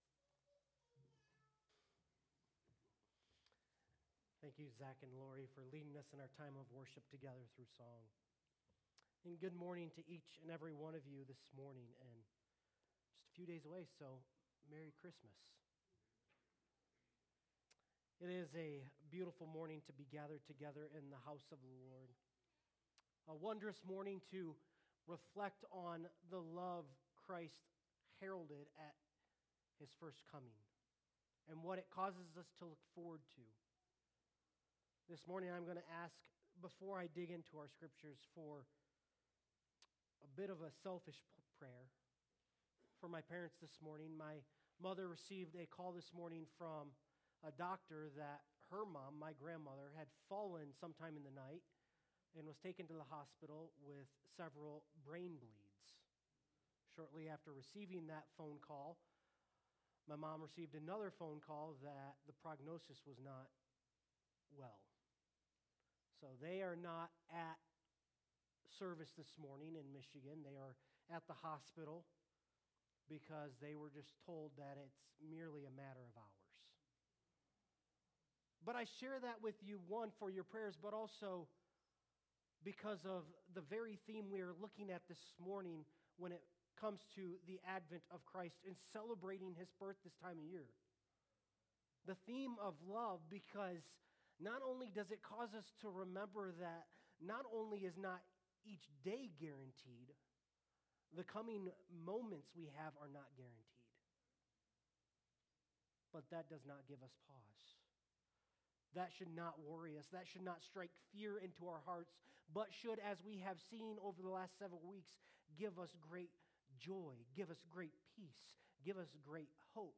Sermons by Aberdeen Baptist Church